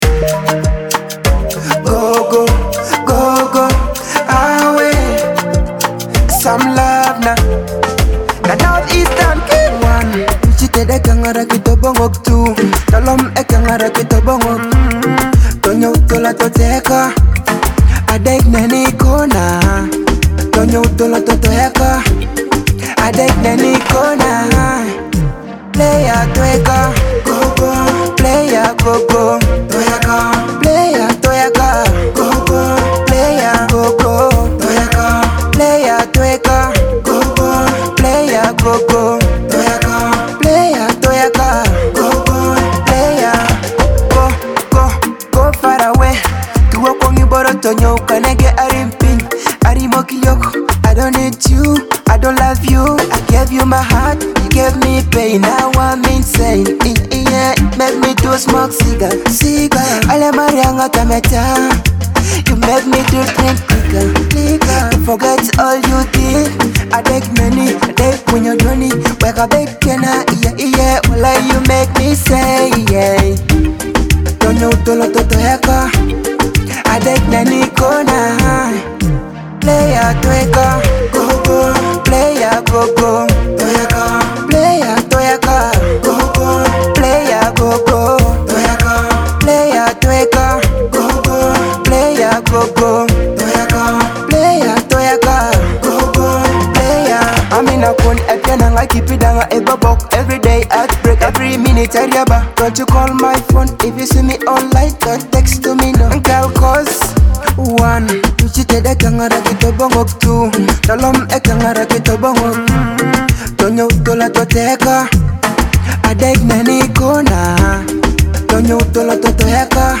a catchy blend of smooth vocals and vibrant beats.
With vibrant rhythms and infectious melodies